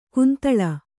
♪ kuntaḷa